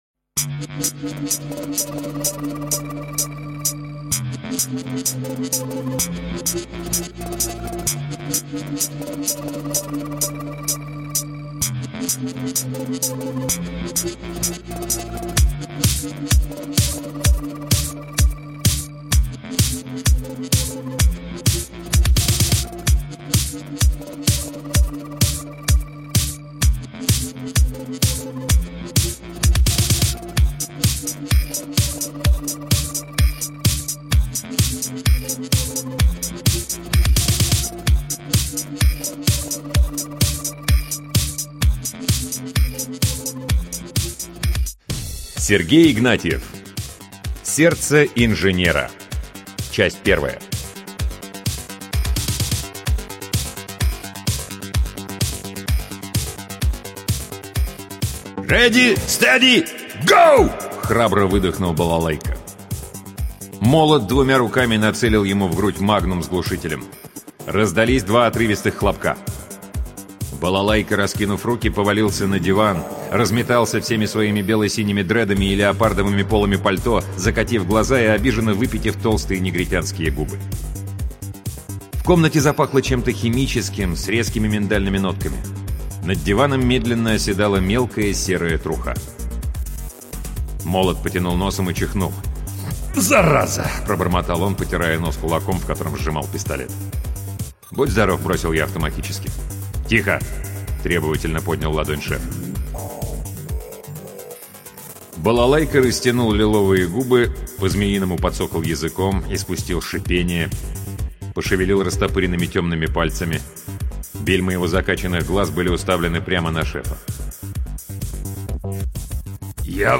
Аудиокнига Сергей Игнатьев — Сердце инженера